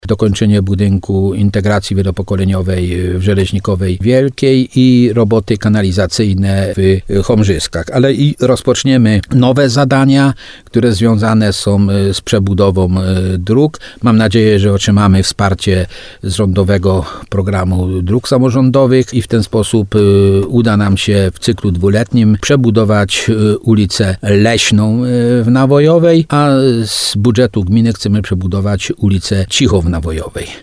Budżet gminy Nawojowa jest na miarę możliwości, a nie chęci – mówił w programie Słowo za słowo na antenie RDN Nowy Sącz wójt Stanisław Kiełbasa. Samorząd od lat nie zaciąga kredytów.